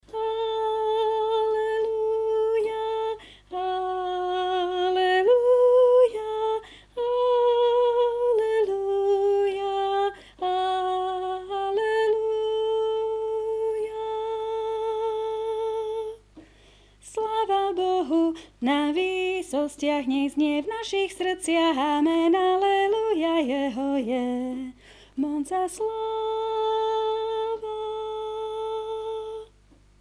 00:00 00:00 MP3 na stiahnutie Prišiel Ježiš (Soprán) Prišiel Ježiš (Alt) Prišiel Ježiš (Tenor) Prišiel Ježiš (Bass)
Prisiel_Jezis-Bass.mp3